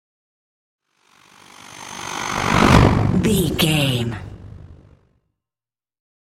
Scifi whoosh pass by chopper
Sound Effects
Fast paced
futuristic
pass by